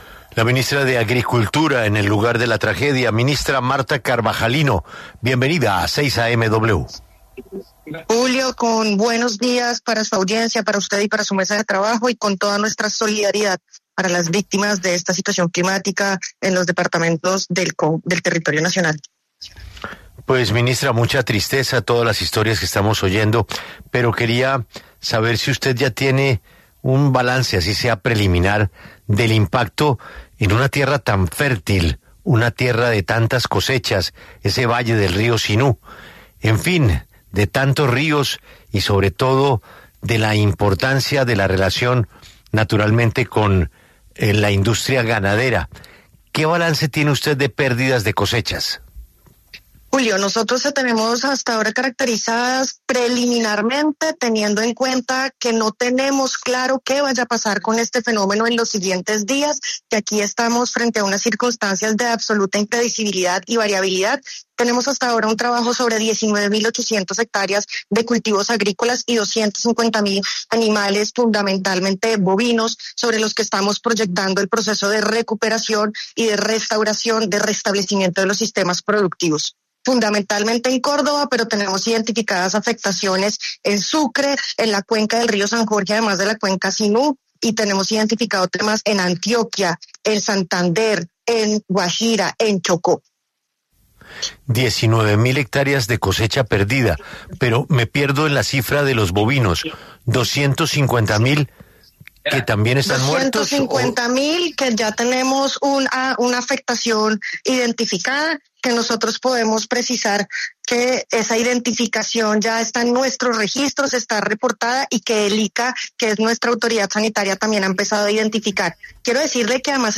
La ministra de Agricultura, Martha Carvajalino, habló en 6AM W sobre el balance de la emergencia climática en Córdoba
En entrevista con 6AM W y Julio Sánchez Cristo, la ministra de agricultura, Martha Carvajalino dio un reporte de las pérdidas en este sector y de algunos alivios en los que se está trabajando.